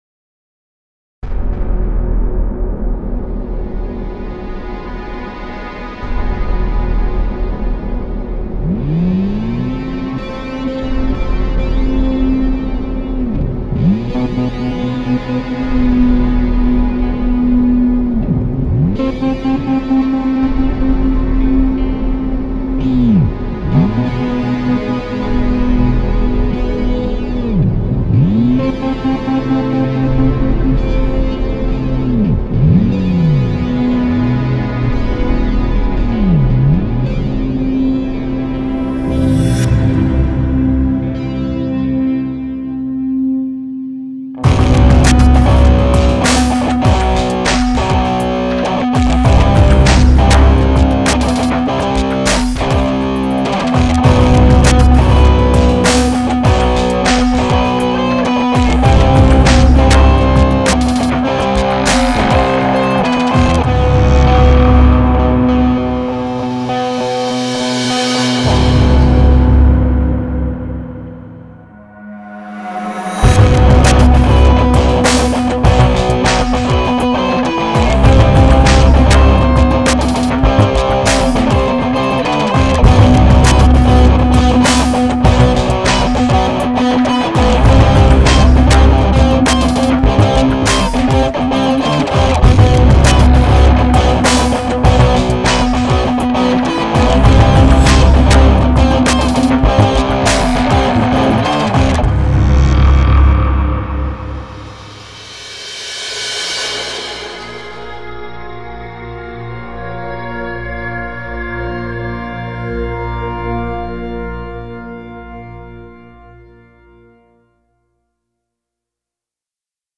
Et voilà, pas mal à l'arrach, un morceau en une passe chacun: